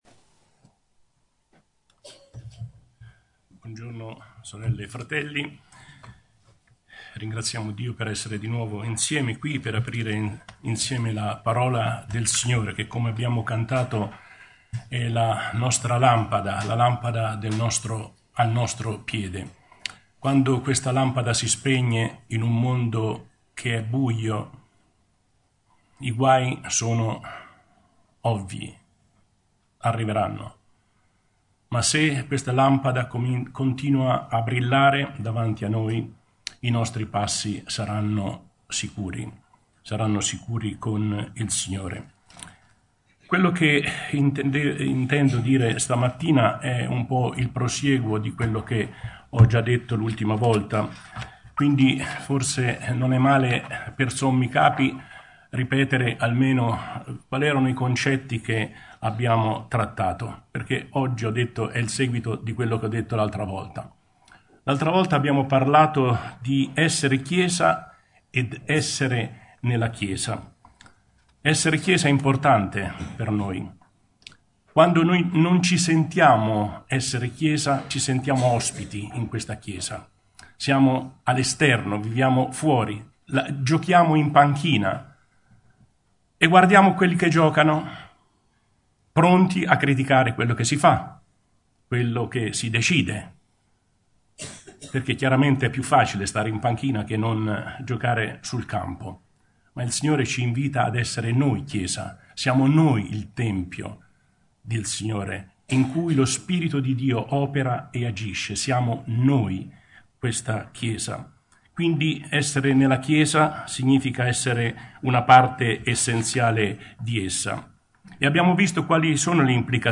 Efesini 4:11-16 Tipo Di Incontro: Domenica Topics